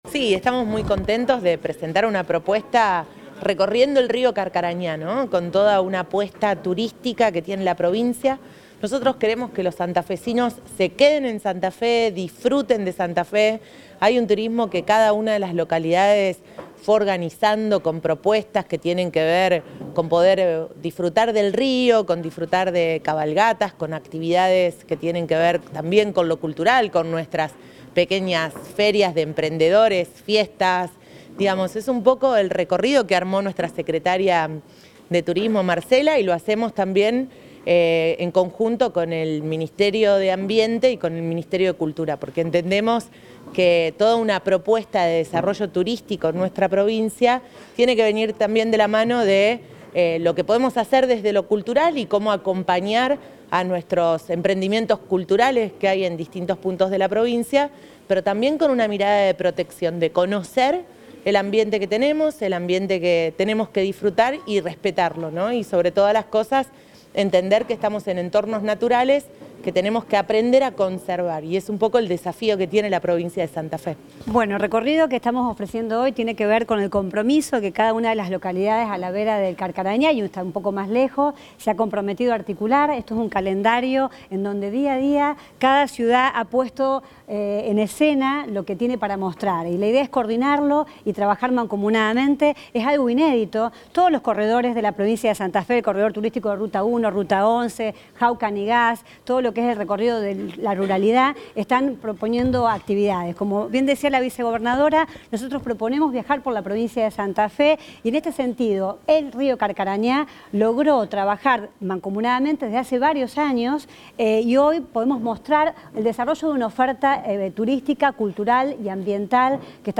En en marco de una actividad desarrollada en el Acuario de Rosario, la vicegobernadora Gisela Scaglia, y la secretaria provincial de Turismo, Marcela Aerberhard, presentaron propuestas turísticas para disfrutar del Invierno en Santa Fe.
Declaración Scaglia y Aeberhard